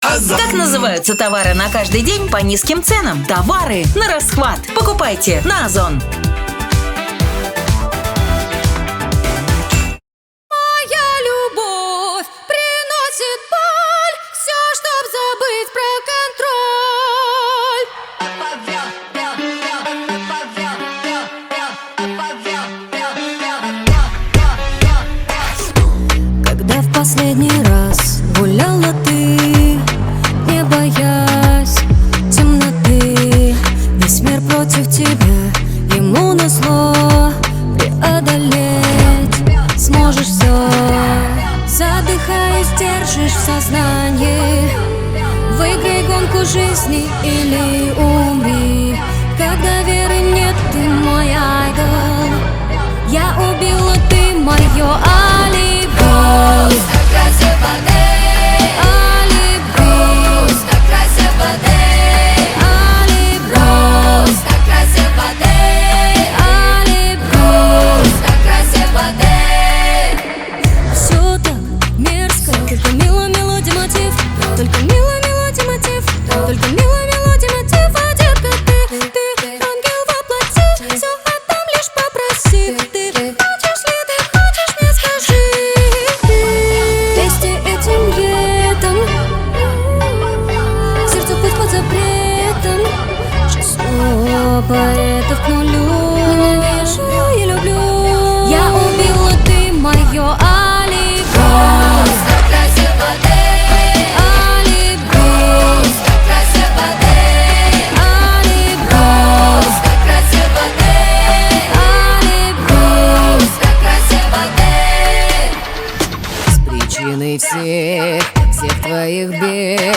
кавер на русском)/(Russian cover